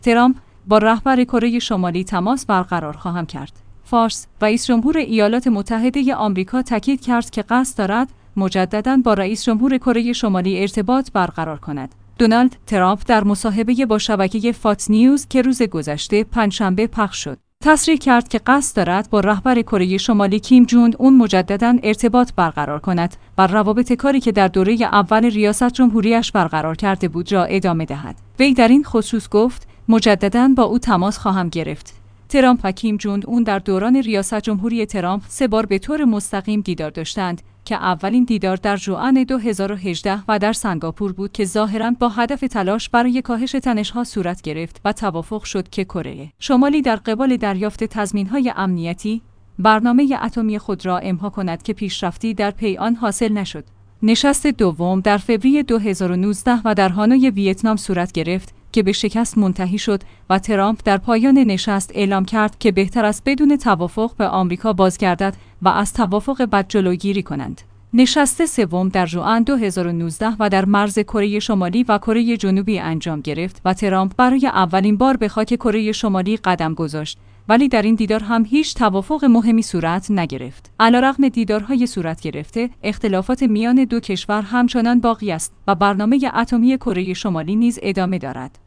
دونالد ترامپ در مصاحبه‌ با شبکه «فاکس‌نیوز» که روز گذشته (پنجشنبه) پخش شد، تصریح کرد که قصد دارد با رهبر کره شمالی کیم جونگ اون مجددا ارتباط برقرار کند و روابط کاری که در دوره اول ریاست‌جمهور